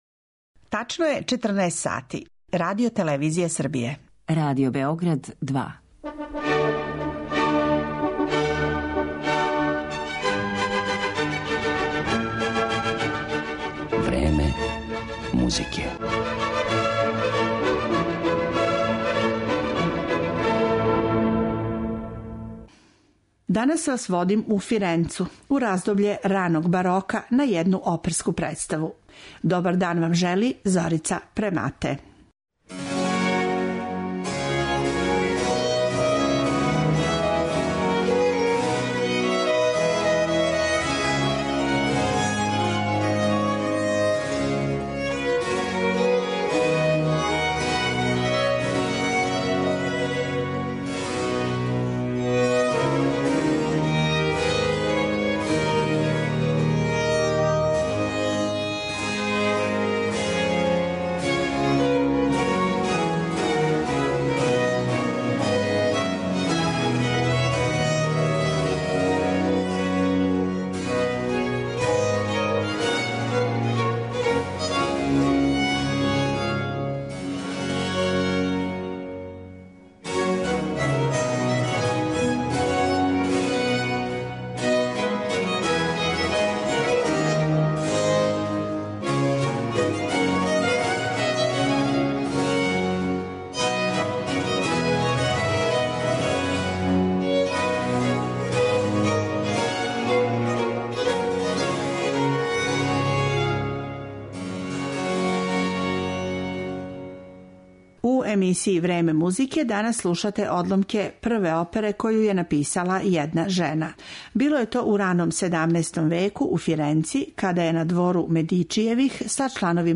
У емисији 'Време музике' слушаћете одломке из прве сачуване опере коју је написала жена.